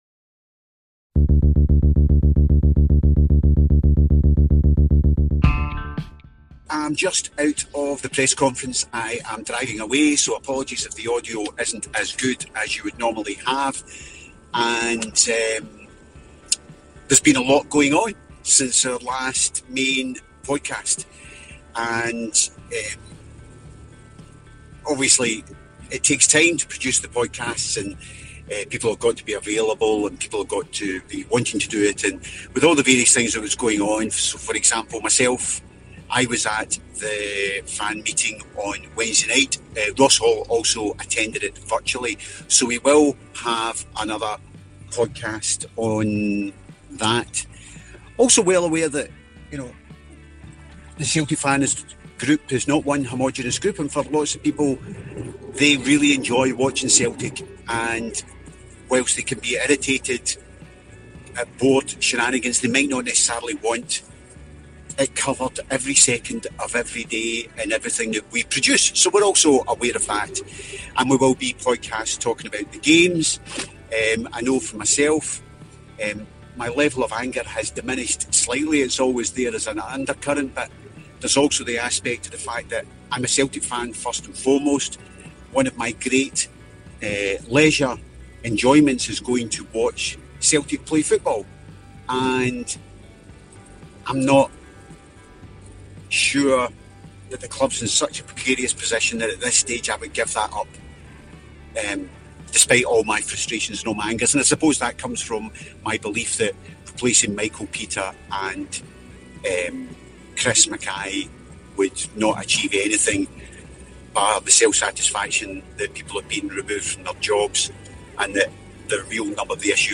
Brendan Rodgers Presser
There’s no other way to describe today’s press conference.